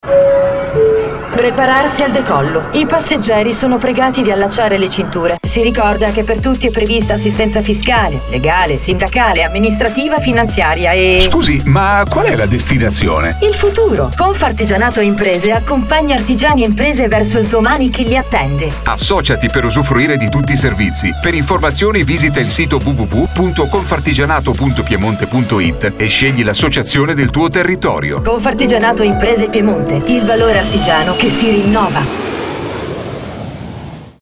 Spot radiofonico 2023 “Confartigianato verso il futuro” – ascoltalo